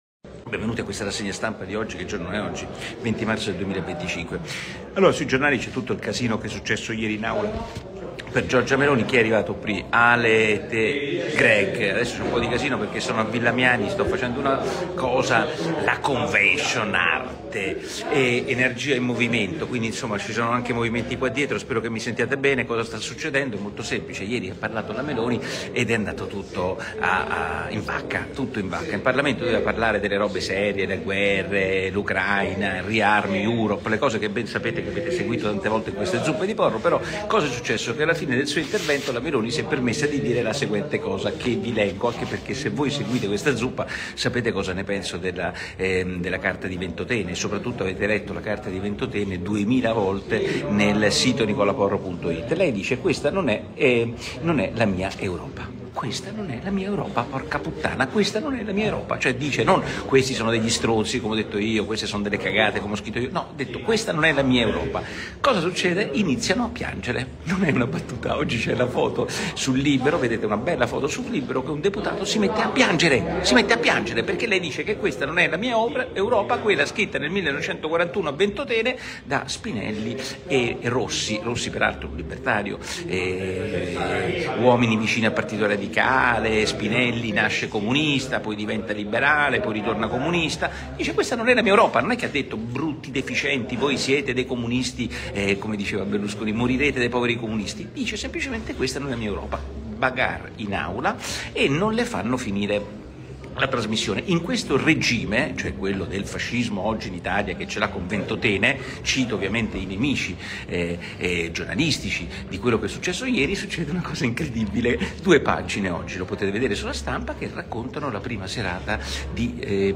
Il podcast ufficiale di Nicola Porro, che tutti i giorni cucina una rassegna stampa per i suoi ascoltatori.